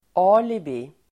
Uttal: ['a:libi]